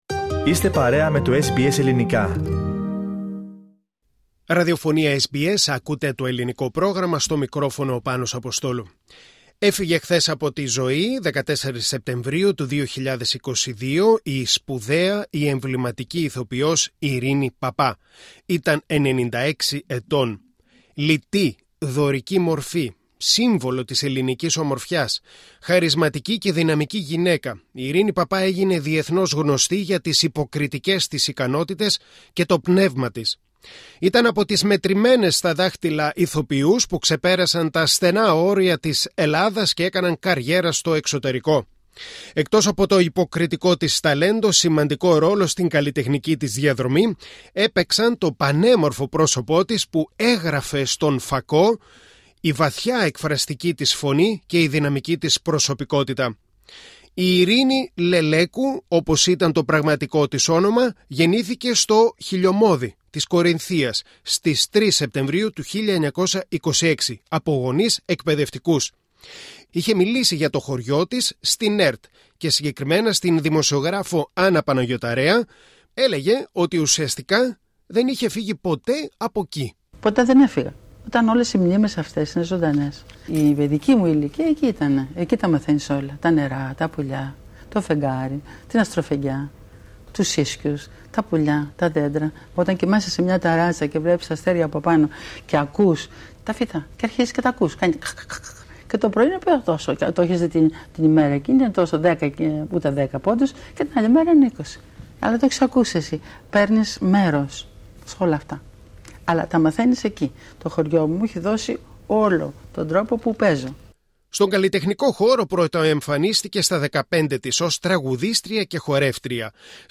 Η Ειρήνη Παπά, η εμβληματική ηθοποιός του θεάτρου και του κινηματογράφου, έφυγε από την ζωή. Φιλοξενούμε αποσπάσματα από συνεντεύξεις της όπου μιλά για την καριέρα της, το αγαπημένο της χωριό, τον ρόλο της γυναίκας και για τα γηρατειά.